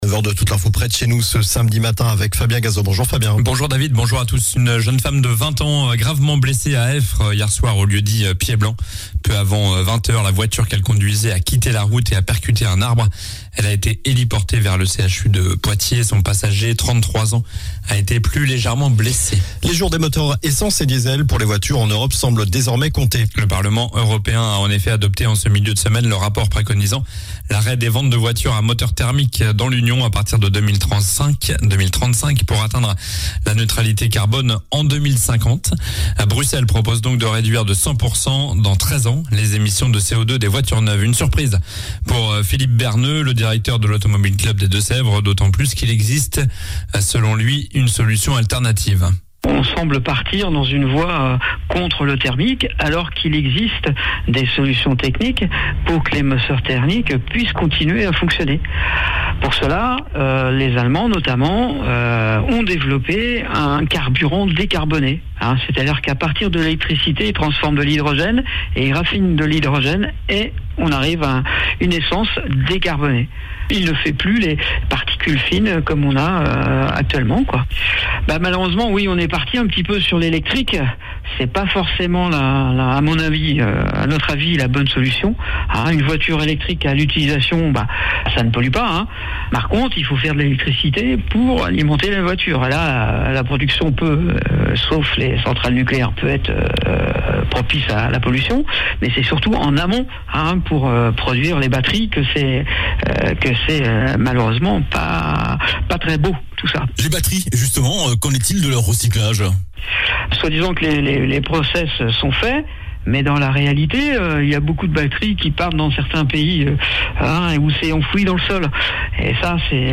Journal du samedi 11 juin